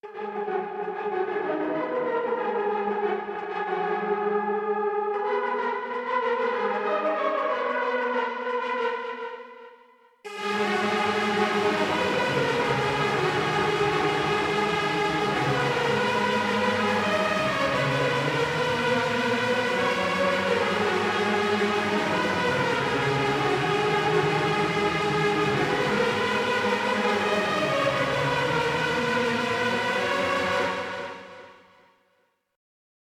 Mainlead_reverb01.ogg